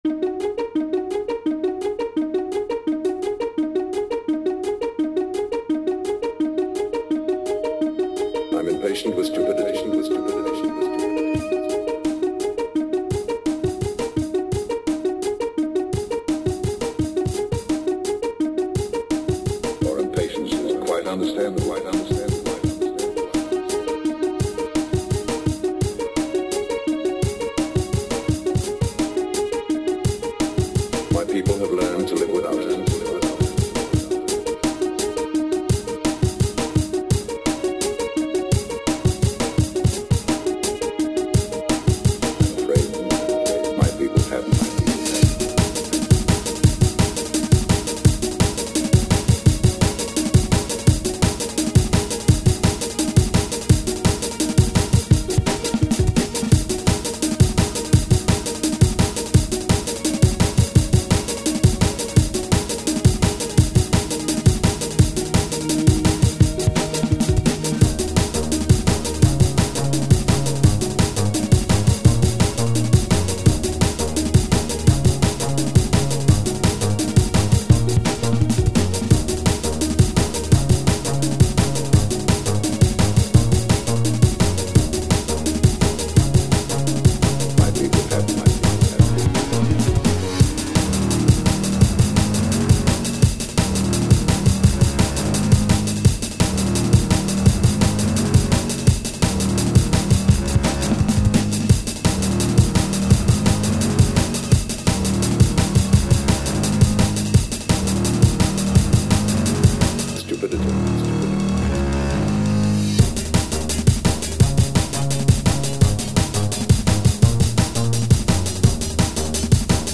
Lots of strings and a nice break in this drum and bass tune.